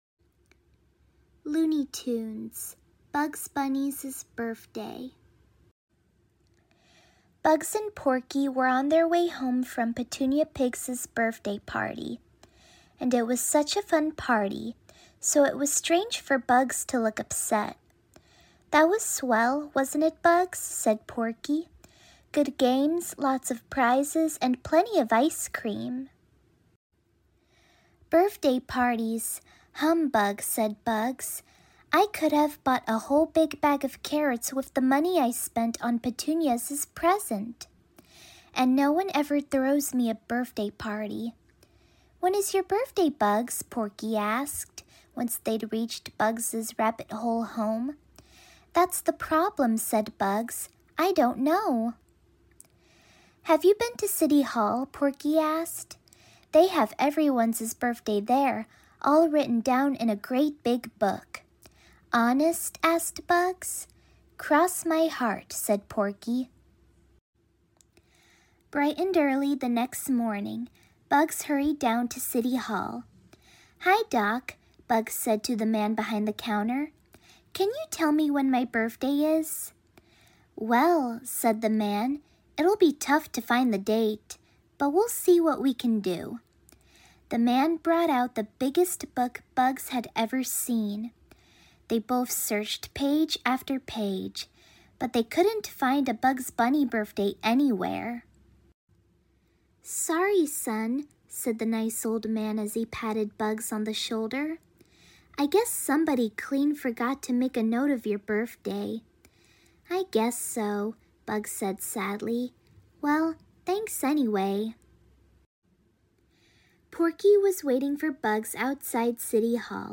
Read along with me as I read the Bugs Bunny birthday book! Also, happy birthday to Bugs Bunny!